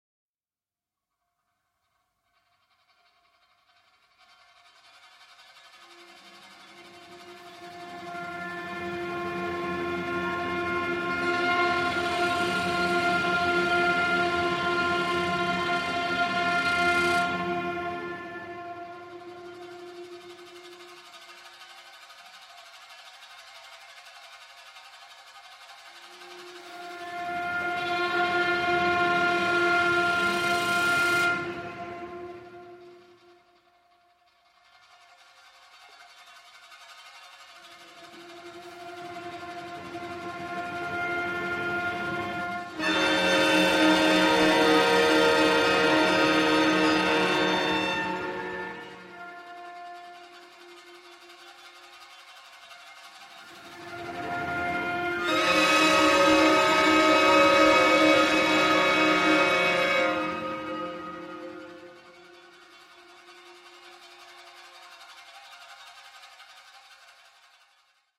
shamisen